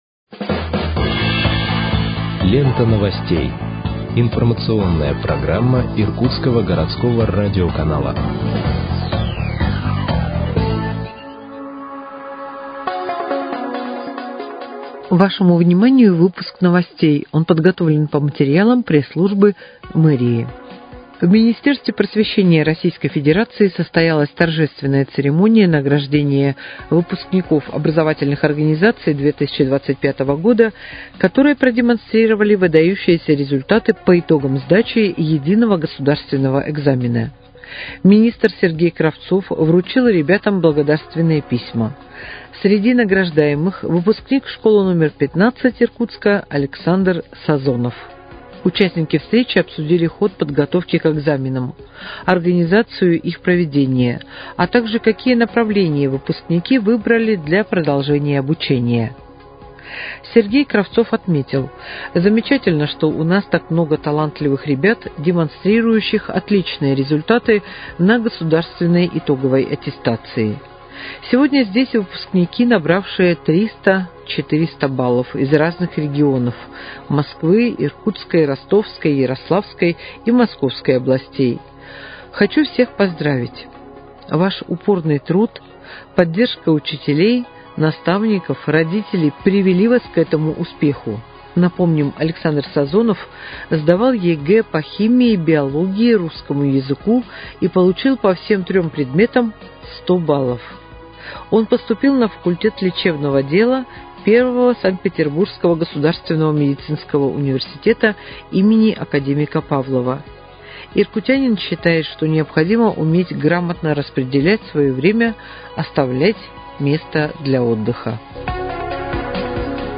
Выпуск новостей в подкастах газеты «Иркутск» от 29.08.2025 № 2